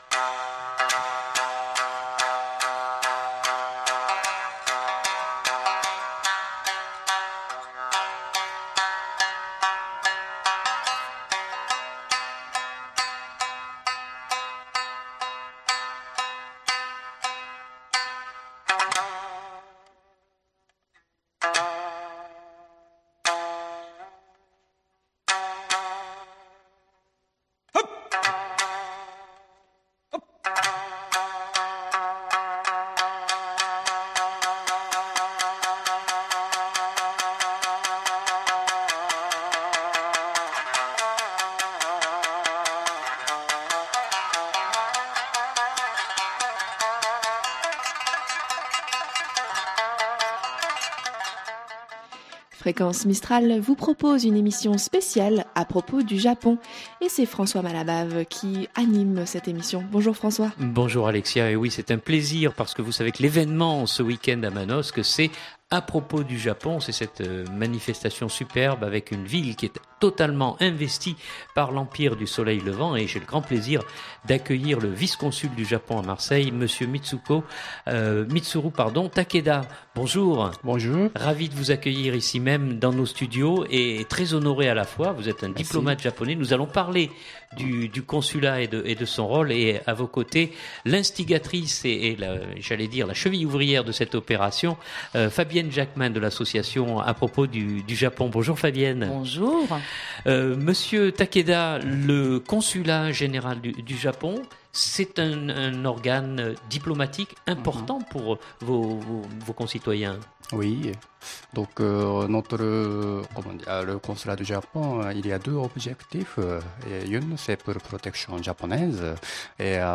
Pendant 3 jours, Manosque va vibrer à la culture de l'archipel nippon sous toutes ses formes. Invités d'une émission spéciale consacrée à l'événement